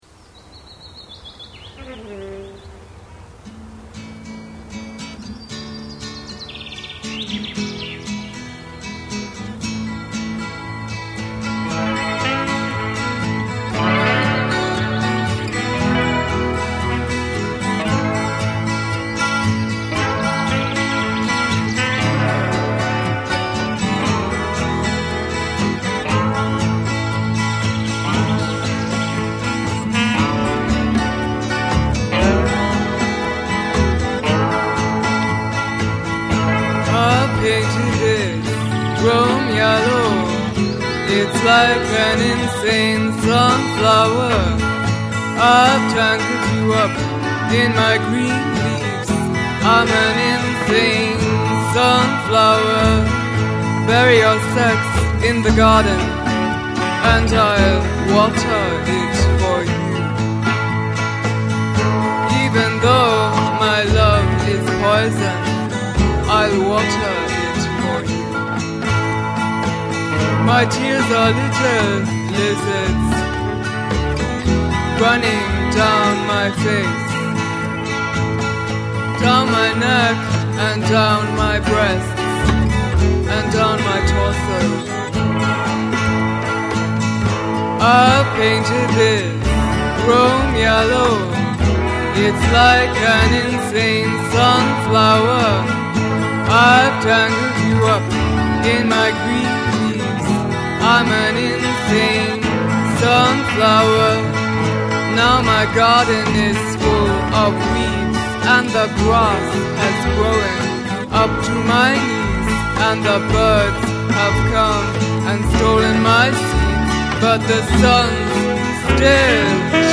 Sax
Guitar
Double Bass + Slide Guitar